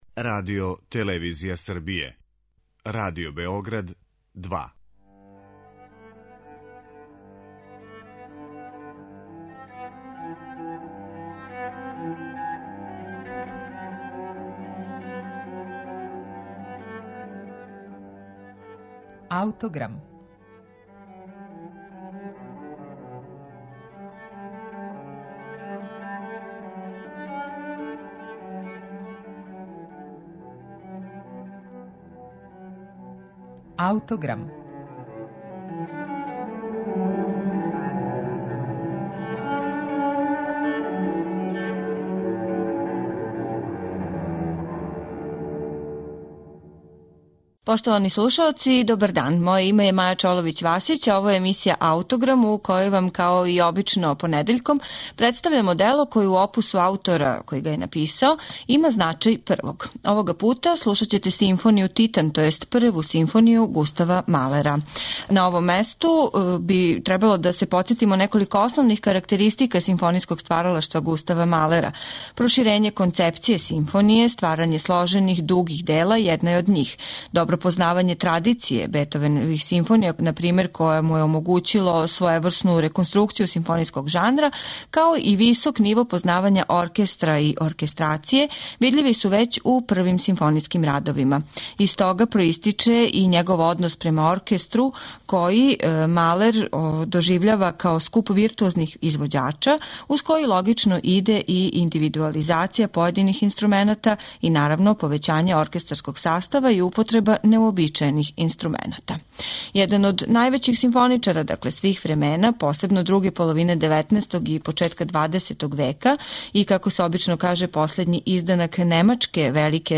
Симфонија у Де-дуру